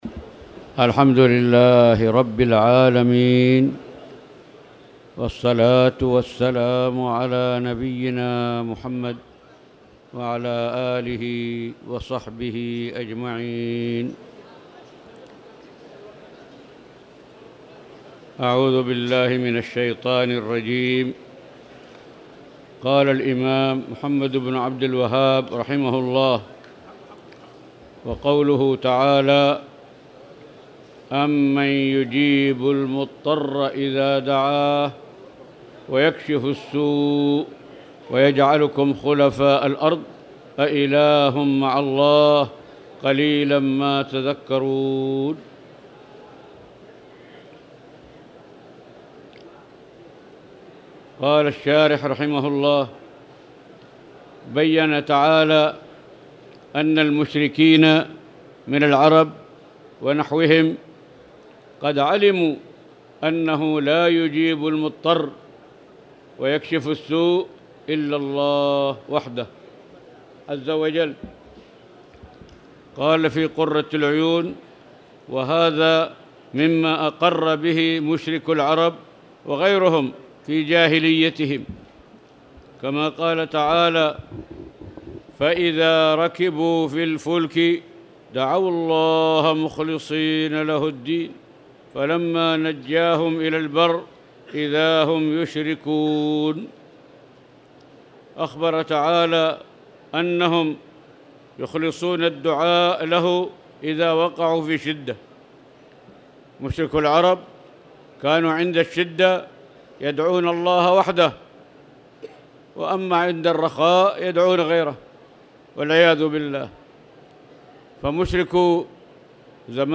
تاريخ النشر ٧ رمضان ١٤٣٧ هـ المكان: المسجد الحرام الشيخ